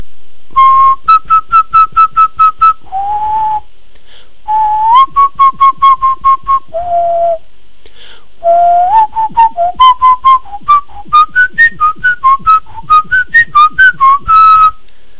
silbido.wav